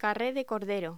Locución: Carré de cordero
voz